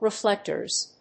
/rɪˈflɛktɝz(米国英語), rɪˈflektɜ:z(英国英語)/